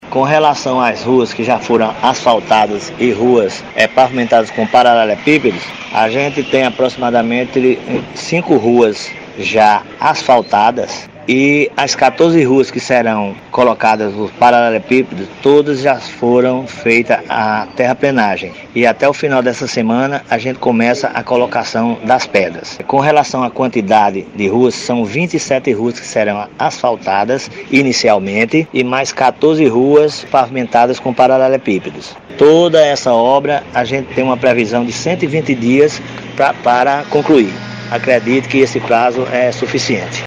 Fala do secretário Municipal de Infraestrutura, Antônio Carlos (Lito) – Download Comentário Comentário Compartilhe isso: WhatsApp E-mail Facebook Mais Telegram Curtir isso: Curtir Carregando...